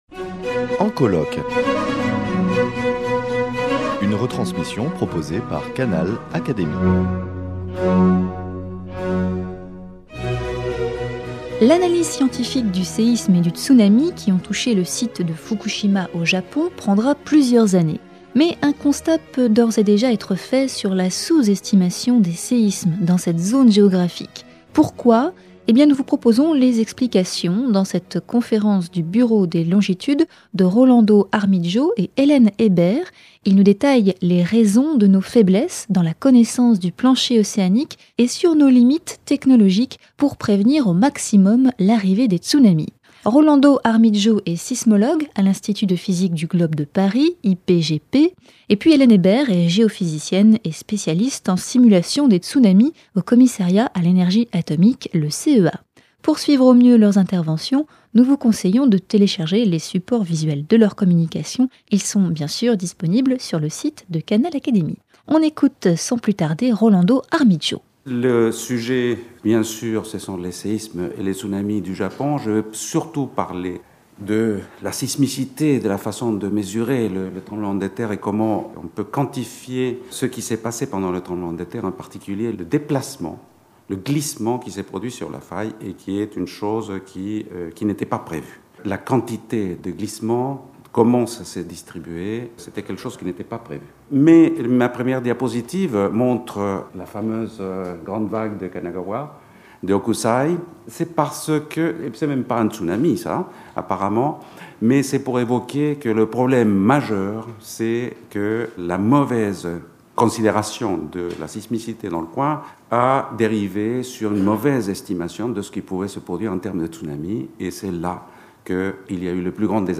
Explications dans cette conférence du Bureau des longitudes.